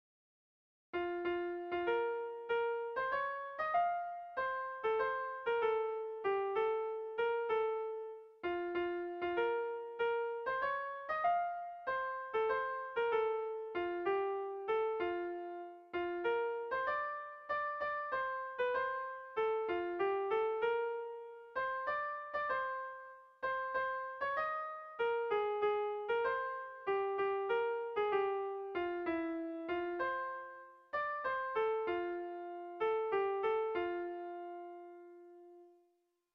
Erlijiozkoa
A1A2BD